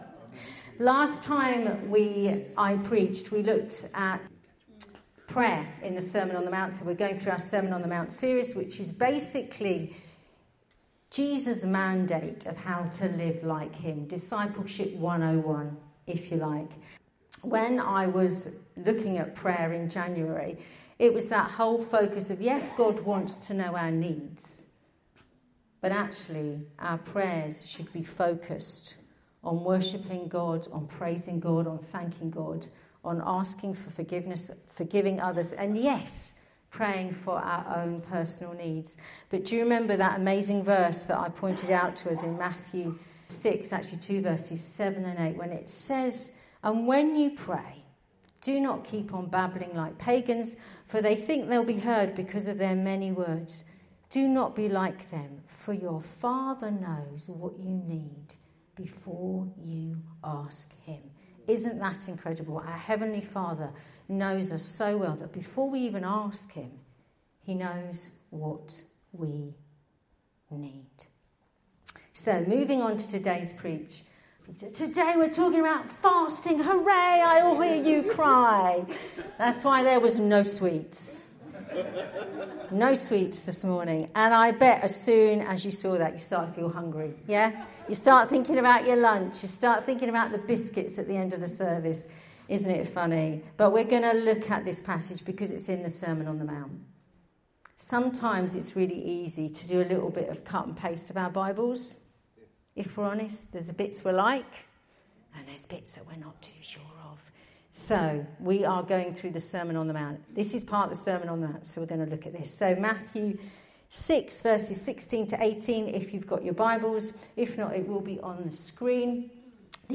A teaching series based on The Sermon on the Mount and entitled, 'Living Like Jesus'.
Teachings from our Sunday that don’t form part of a series.